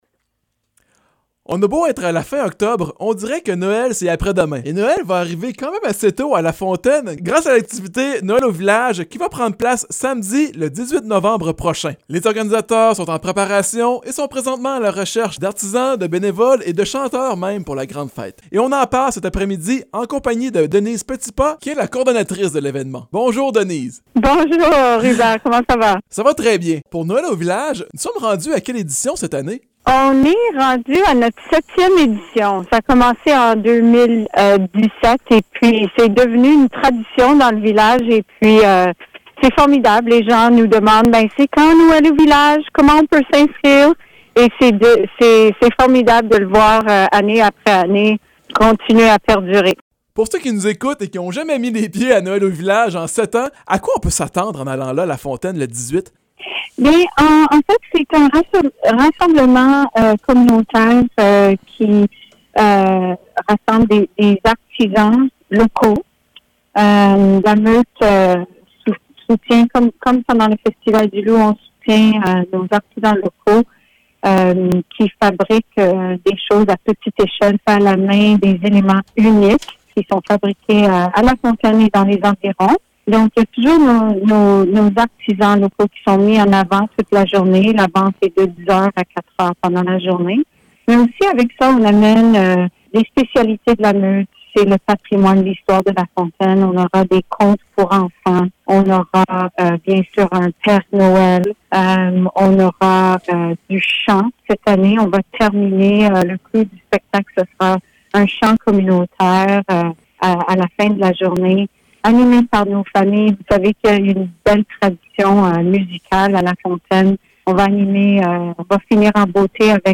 Voici l’entrevue